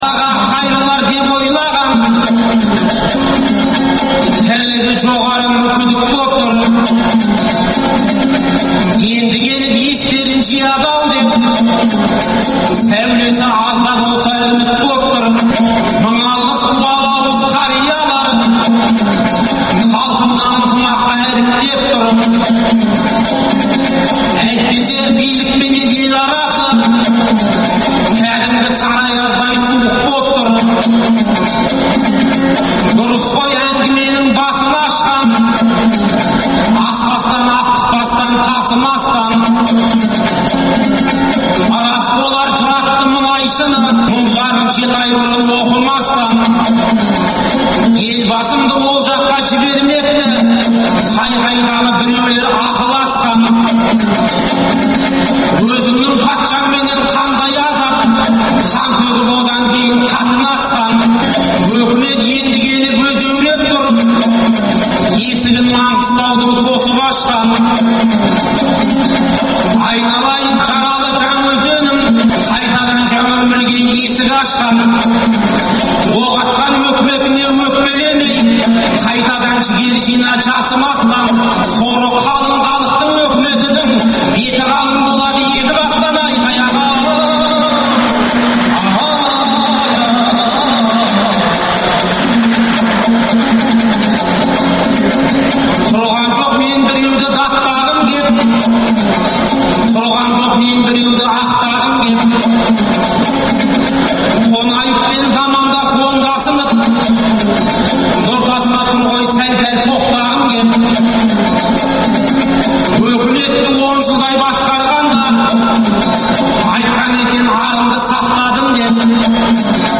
Айтыс -Азаттықта: жалғасы
Бүгін Алматыдағы Балуан Шолақ атындағы спорт сарайында "Қонаевтай ер қайда" деген атпен өткен айтыстан